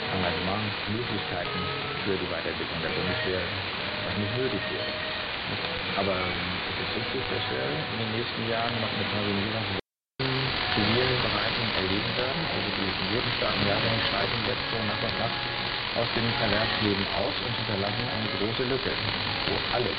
DLF (Deutschlandfunk) on 549 KHz. Here the Cloud-IQ has clearly the upper band with a clean signal. The Perseus is noticeably less clear with local interferences.
The music in the background comes from UR1 from Ukraine. Reception in AM-synchronous and 9 KHz band width.
549khz-AMS-DLF-CloudIQ-Perseus.mp3